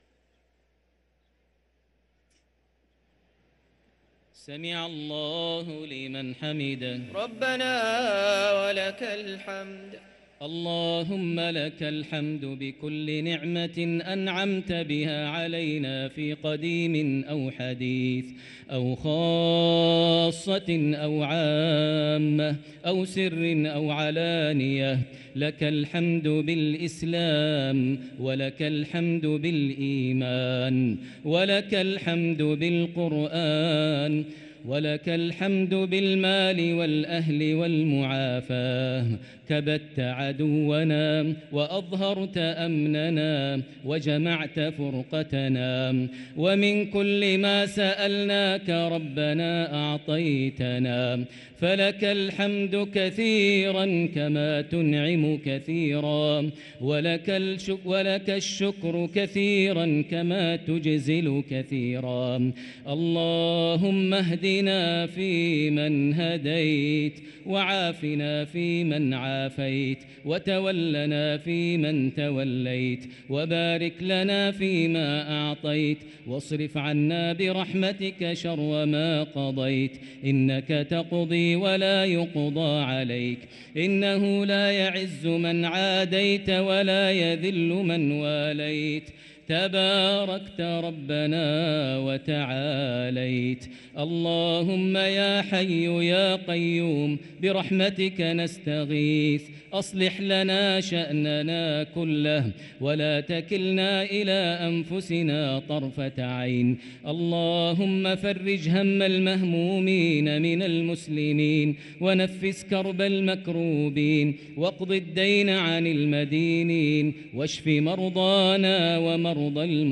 دعاء القنوت ليلة 8 رمضان 1444هـ > تراويح 1444هـ > التراويح - تلاوات ماهر المعيقلي